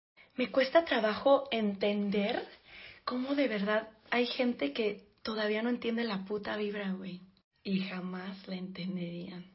me cuesta entender Meme Sound Effect